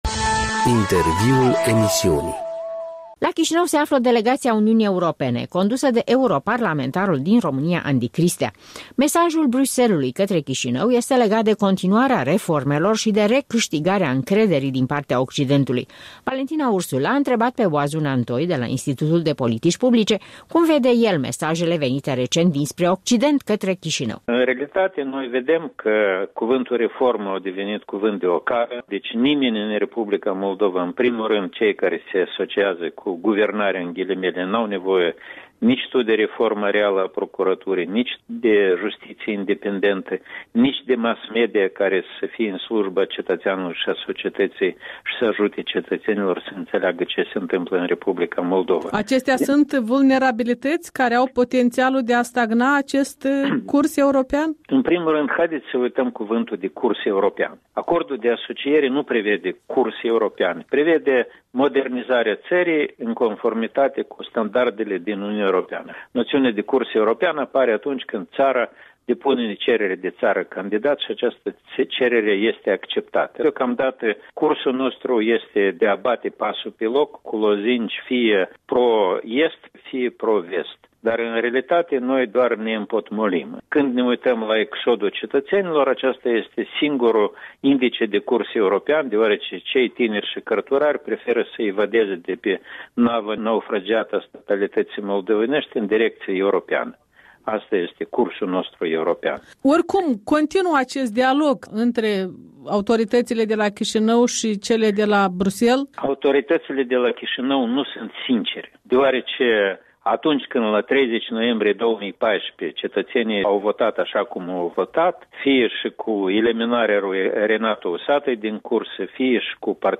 Un interviu cu Oazu Nantoi
Oazu Nantoi în studioul Europei Libere